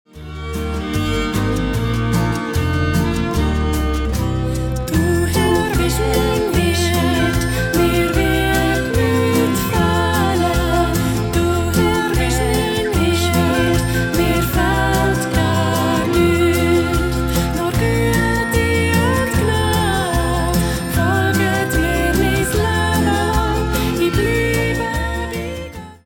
Ihre sanften Lieder in 432Hz
verbreiten eine Atmosphäre von Frieden und Zuversicht